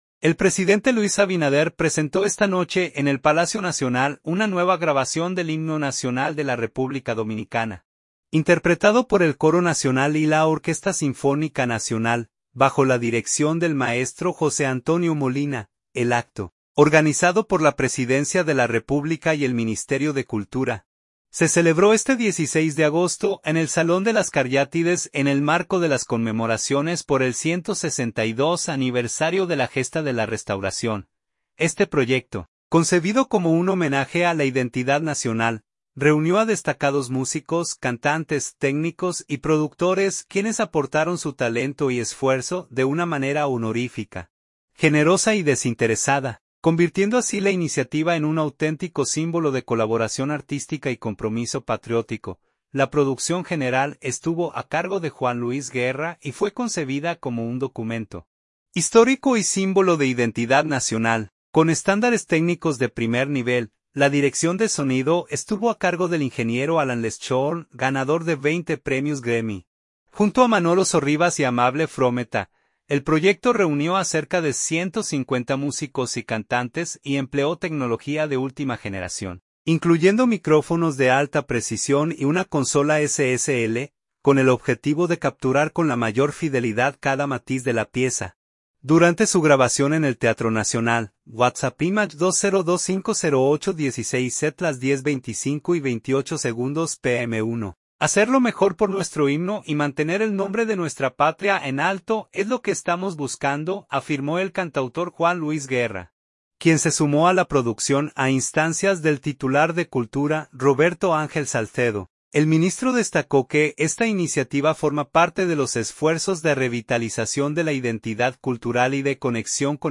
interpretado por el Coro Nacional y la Orquesta Sinfónica Nacional
La producción general estuvo a cargo de Juan Luis Guerra y fue concebida como un documento histórico y símbolo de identidad nacional, con estándares técnicos de primer nivel.
El proyecto reunió a cerca de 150 músicos y cantantes y empleó tecnología de última generación, incluyendo micrófonos de alta precisión y una consola SSL, con el objetivo de capturar con la mayor fidelidad cada matiz de la pieza, durante su grabación en el Teatro Nacional.